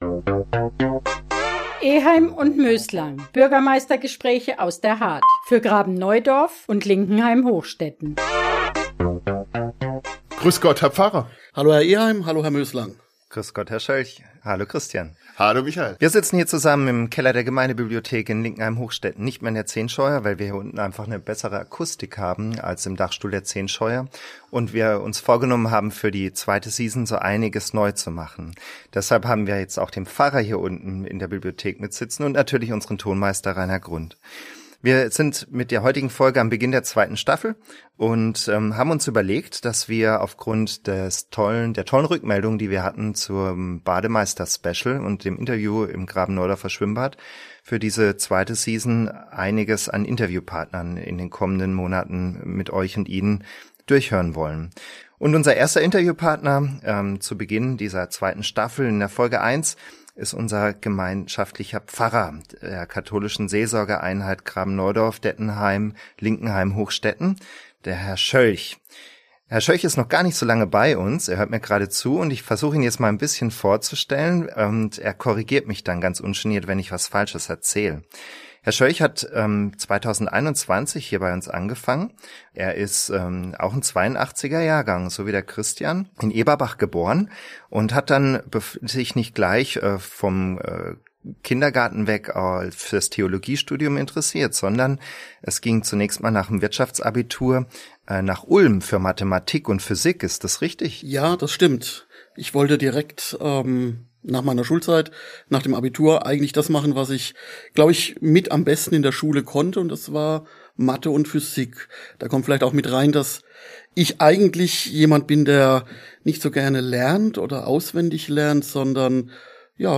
Eheim & Möslang – Der Bürgermeister-Podcast Folge 7 ~ Eheim & Möslang - Bürgermeistergespräche aus der Hardt für Graben-Neudorf und Linkenheim-Hochstetten Podcast